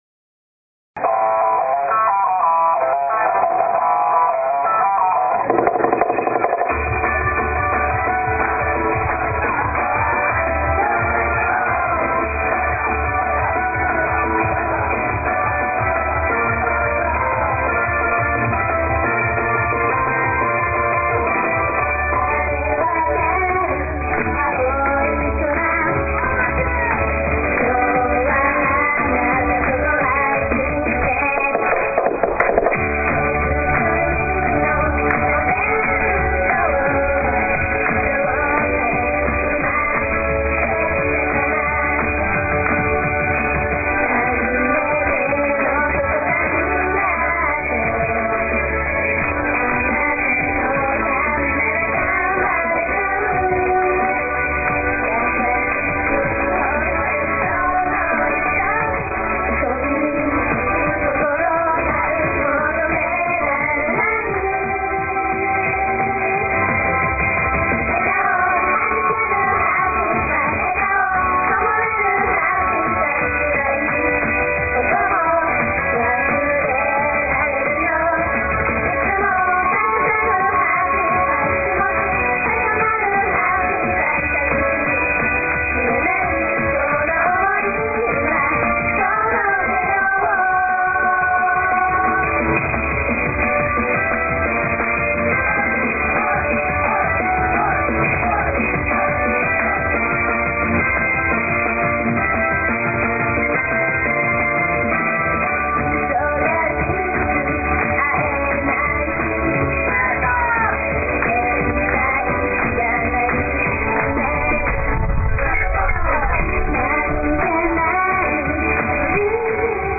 音量これ以上大きくすると割れちゃうので各自聴き方で工夫してください。